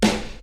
Snare (Sandwitches).wav